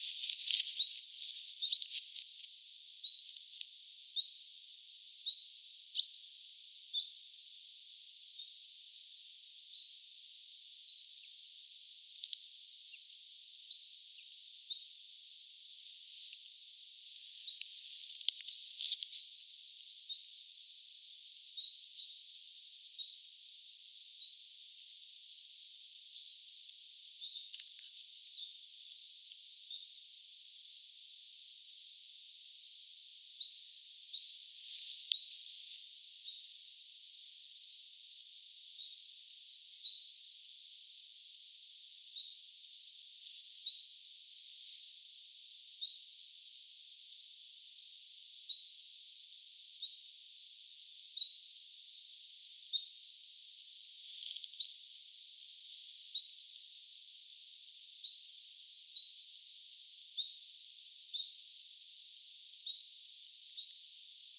bizt-tiltaltin_aantelya2.mp3